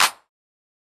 Tm8_Clap43.wav